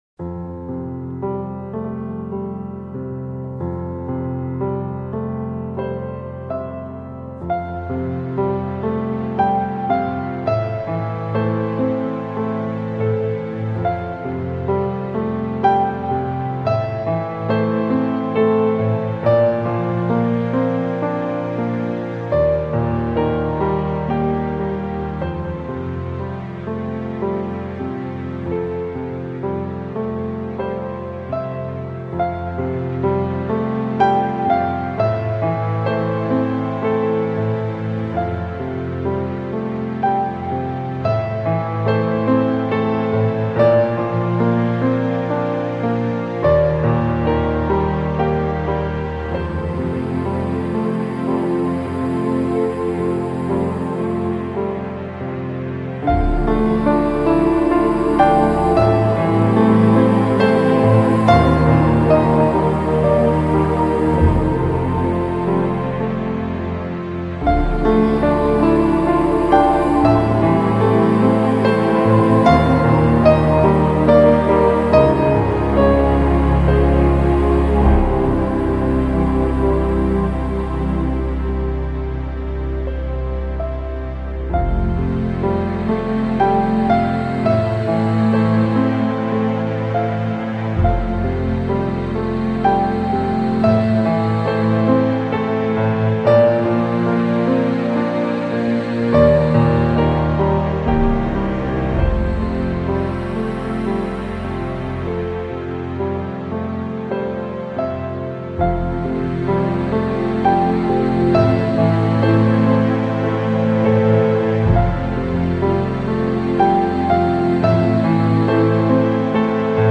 LA MÚSICA TRANQUILA